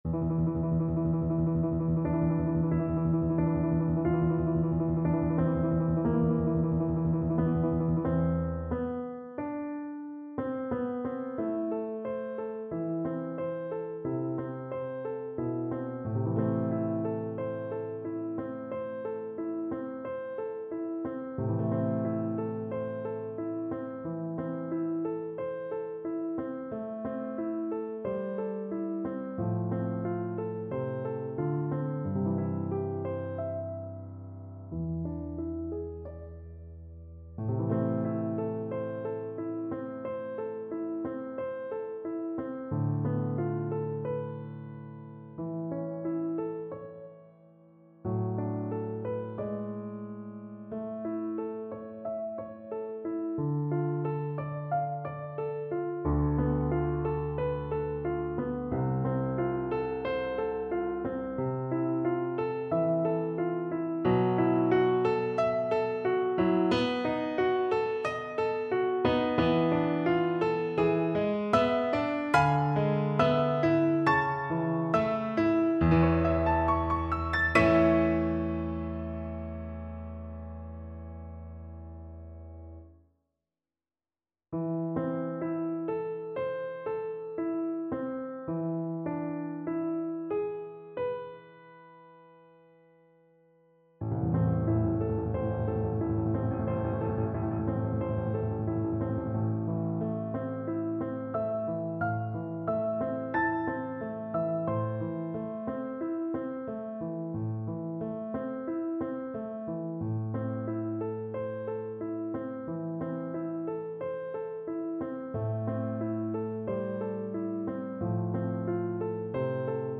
4/4 (View more 4/4 Music)
Lento = 60
Classical (View more Classical Flute Music)